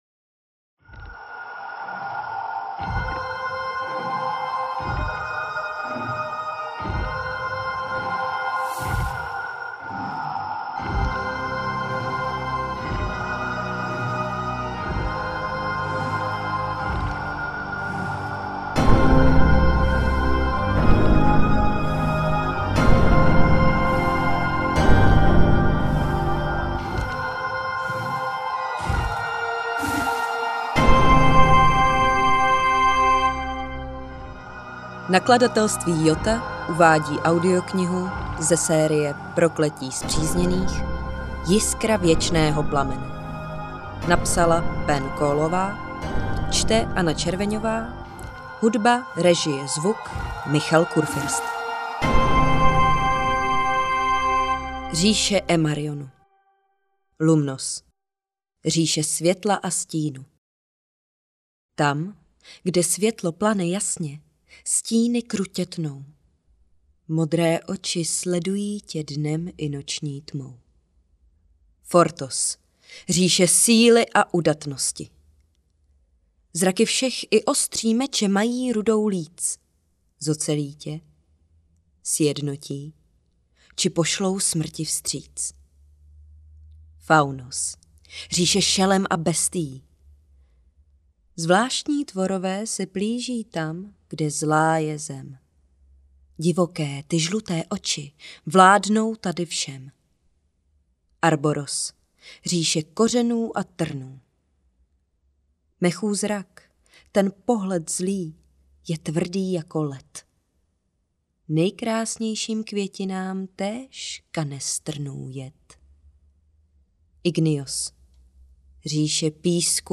Jiskra Věčného plamene audiokniha
Audiokniha Prokletí Spřízněných: Jiskra Věčného plamene, kterou napsala Penn Coleová.
Ukázka z knihy
jiskra-vecneho-plamene-audiokniha